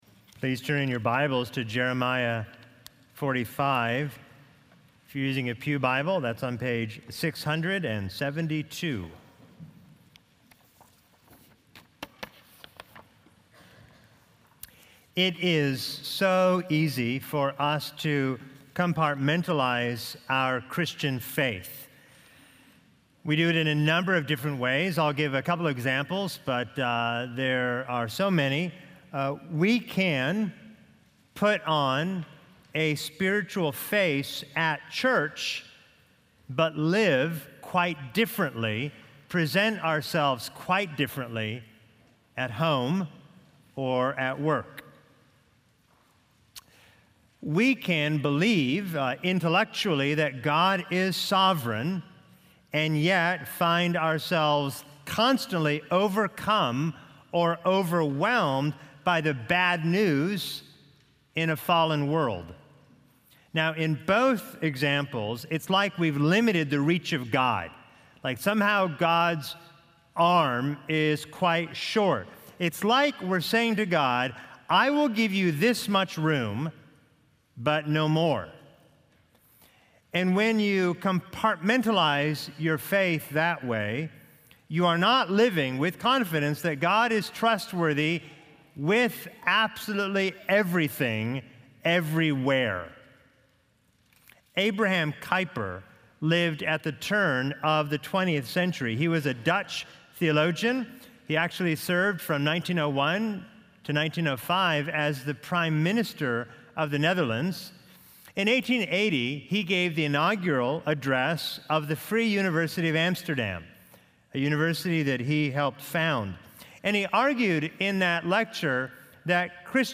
Video & Sermon Audio  Continue reading